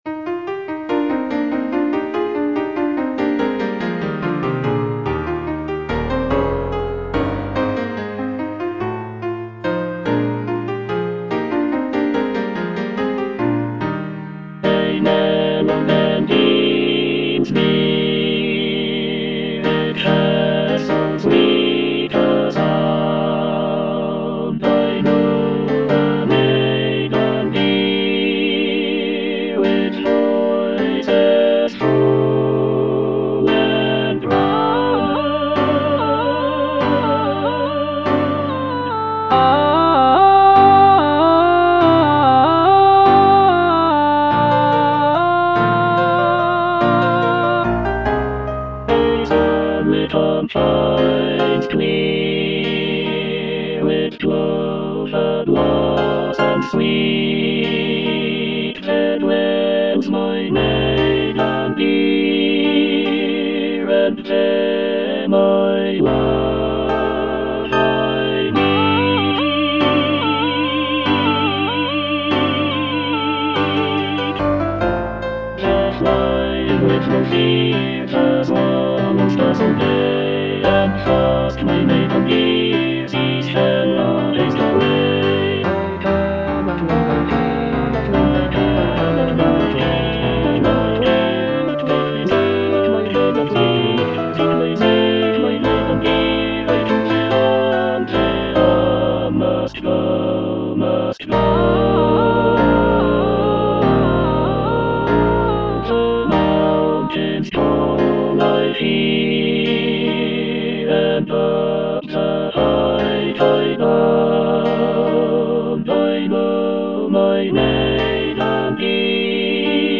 E. ELGAR - FROM THE BAVARIAN HIGHLANDS On the alm (alto I) (Emphasised voice and other voices) Ads stop: auto-stop